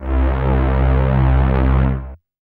RAVE-SYN 008